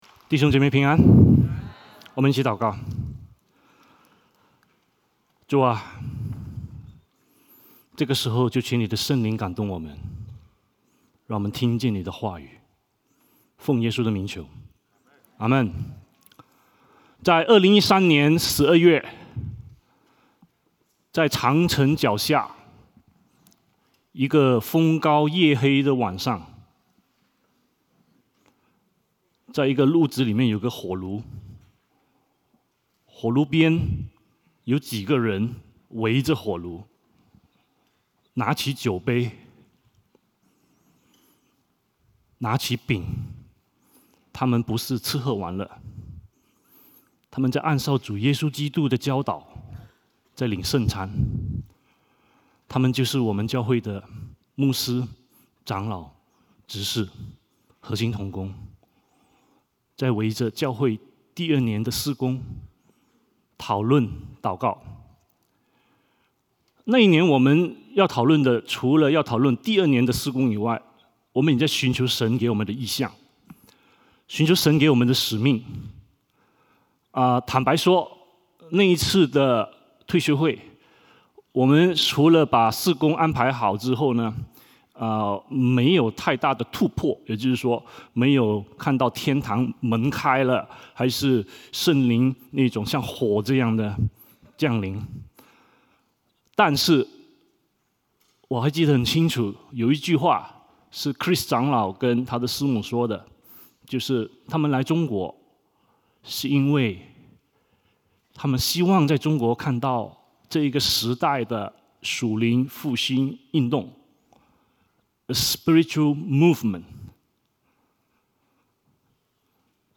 主日证道